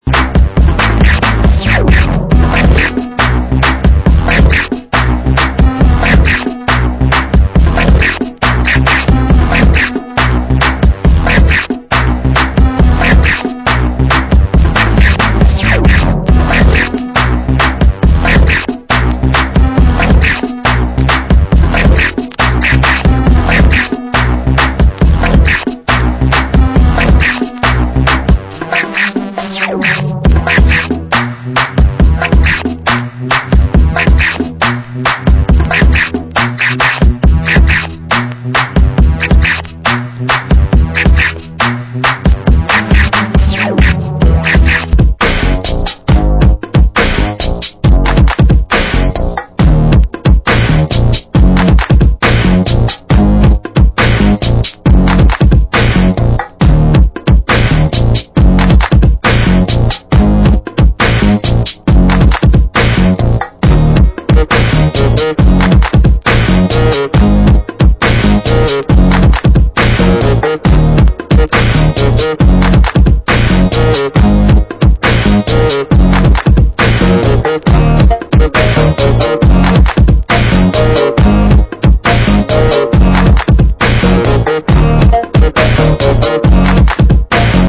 Genre: Grime